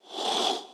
SFX_Door_Slide_06.wav